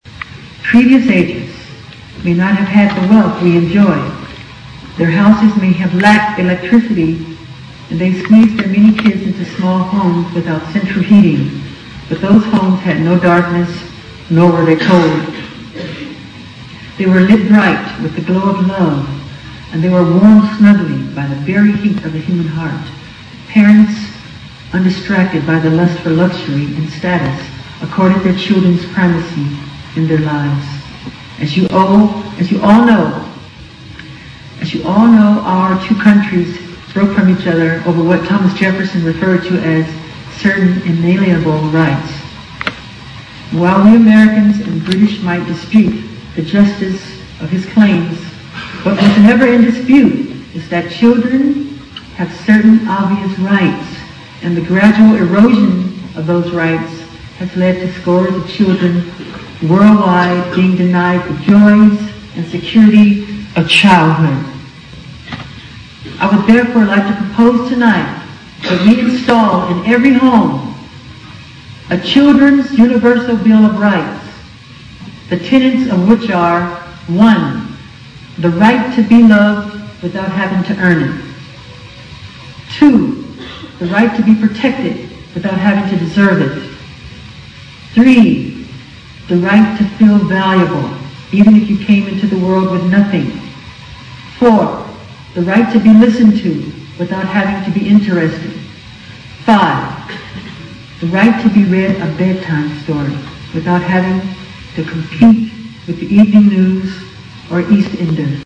名校励志英语演讲 53:拯救世界 拯救儿童 听力文件下载—在线英语听力室